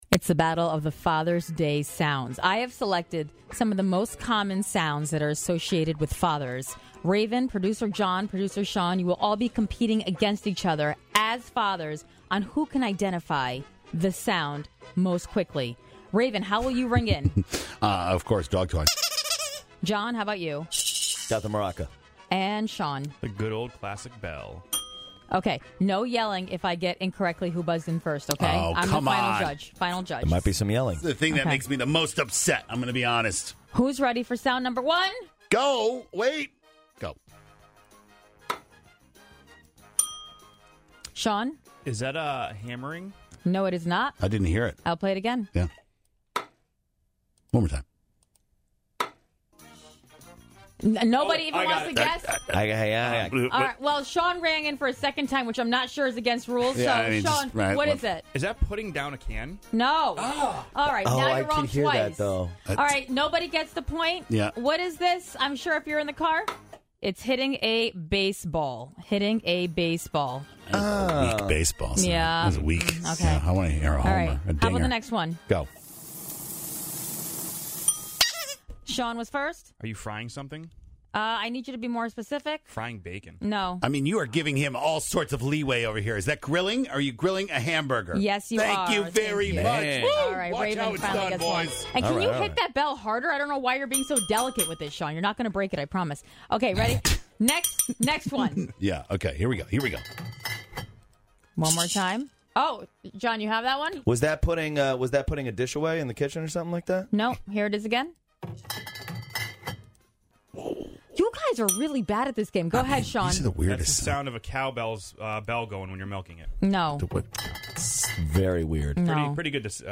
The ten most common sound effects that dads should know… but can they actually recognize the sounds? It’s head-to-head with the dads on the show, who do you think won?